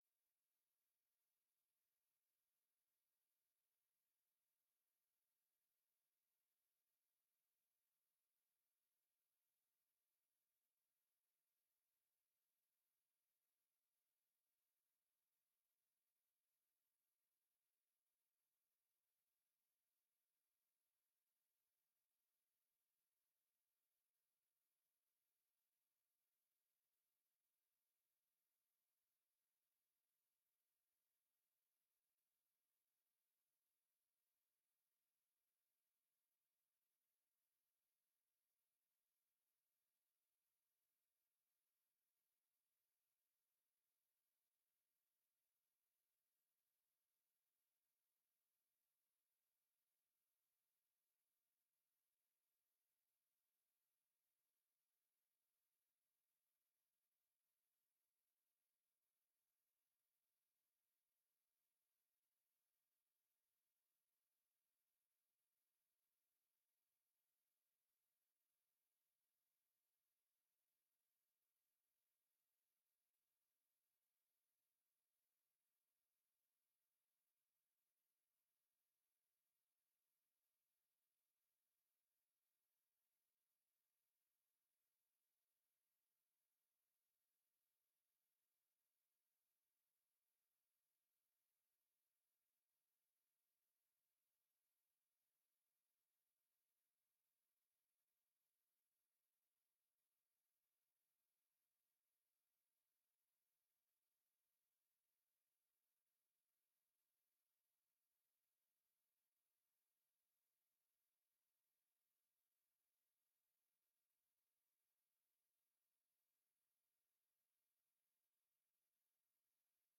Commissie Ruimte en Gebiedsontwikkeling 11 oktober 2022 19:30:00, Gemeente Ouder-Amstel
Download de volledige audio van deze vergadering